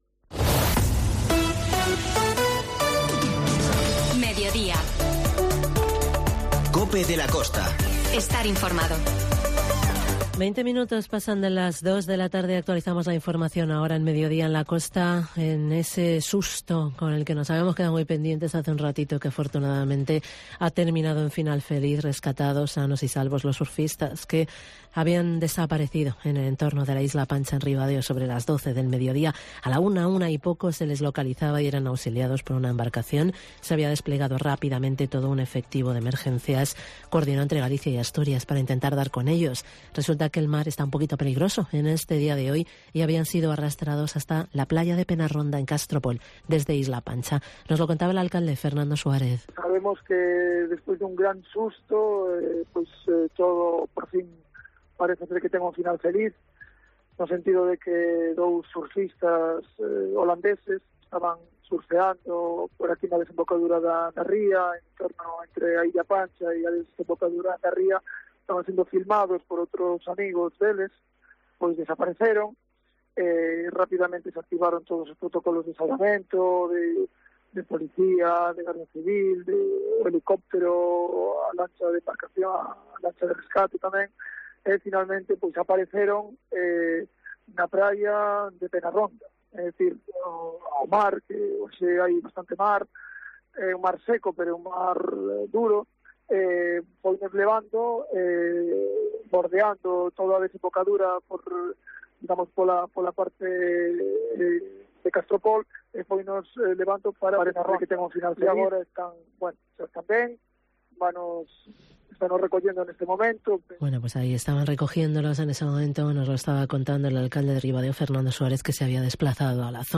COPE de la Costa - Ribadeo - Foz INFORMATIVO